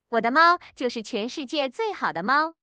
text-to-speech voice-cloning
Fish Speech V1.5-SOTA Open Source TTS